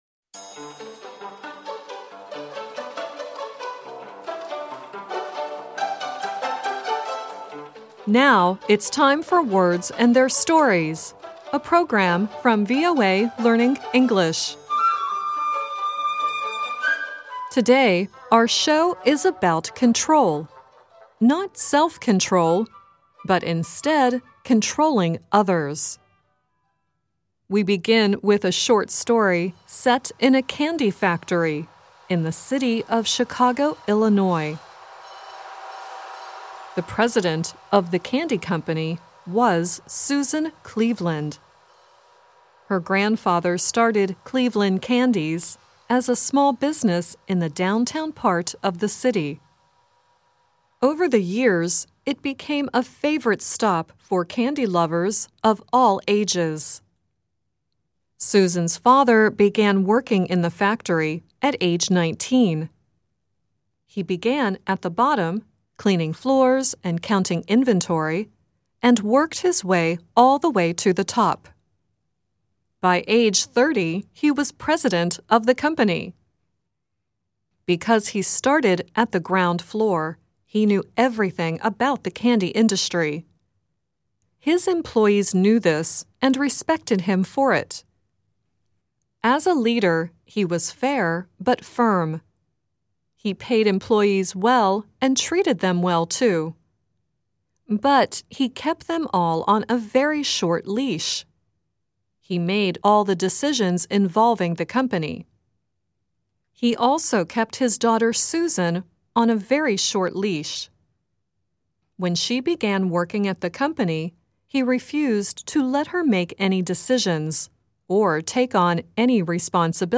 The song at the end is Toby Keith singing "Pull My Chain."